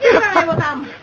Worms speechbanks
fatality.wav